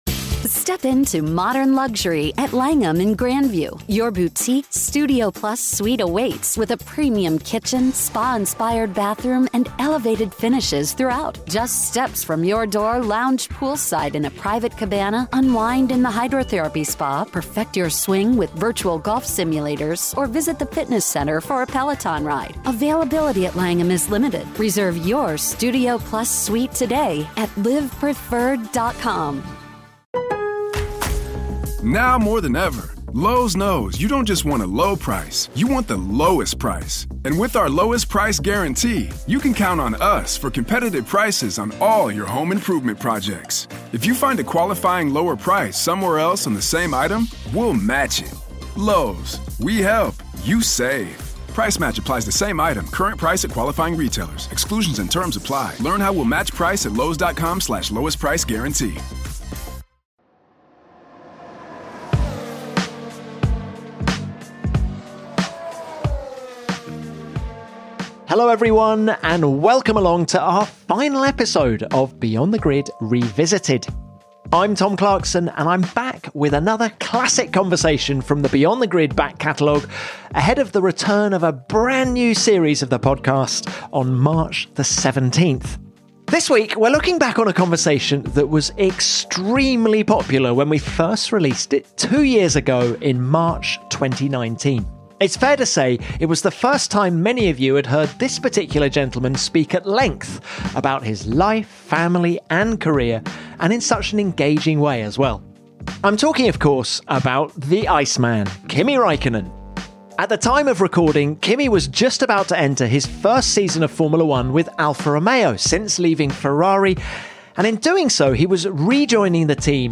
He was in extremely good spirits and, in a rare feature-length interview, was happy to open up on everything from his party boy past to the joys of fatherhood.